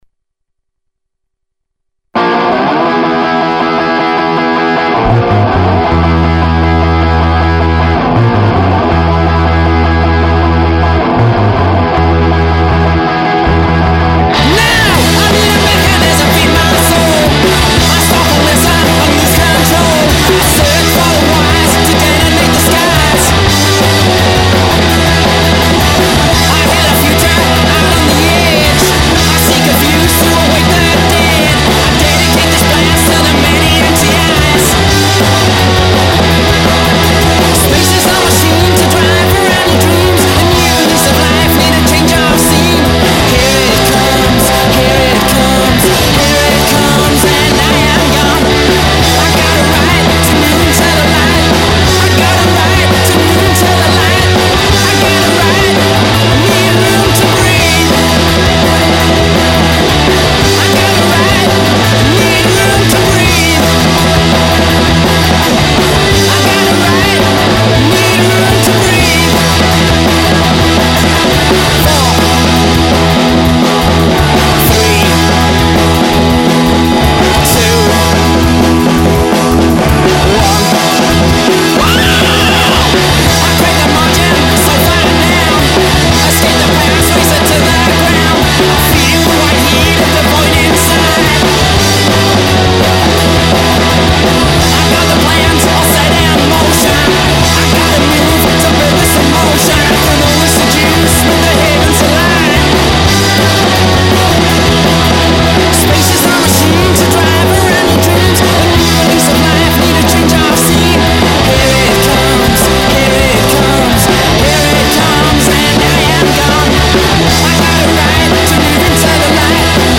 Studio track